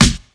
• 00's Rich Top End Hip-Hop Snare One Shot D# Key 07.wav
Royality free steel snare drum sample tuned to the D# note. Loudest frequency: 3316Hz
00s-rich-top-end-hip-hop-snare-one-shot-d-sharp-key-07-hp7.wav